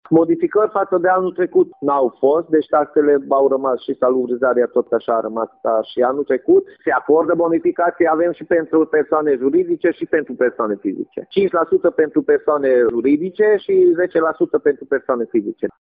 La Gornești, impozitele și taxele locale nu s-au majorat iar bonificațiile se menţin şi anul acesta, spune primarul Kolcsar Gyula: